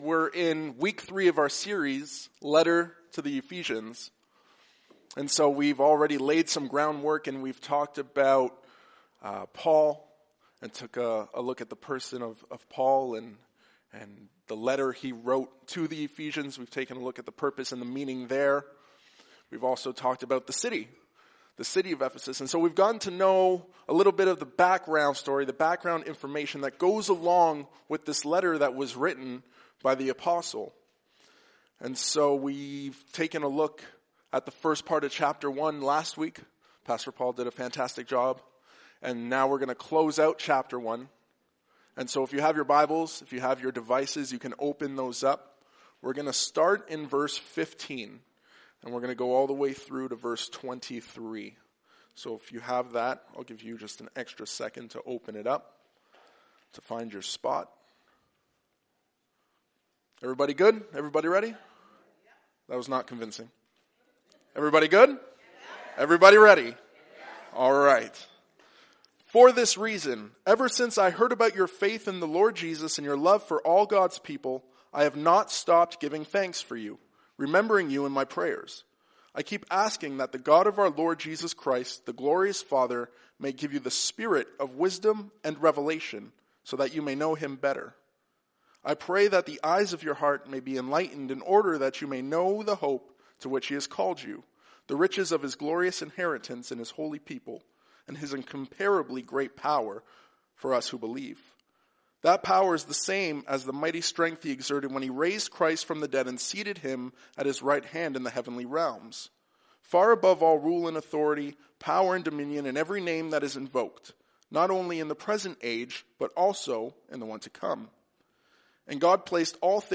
Sermons | Highway Gospel Church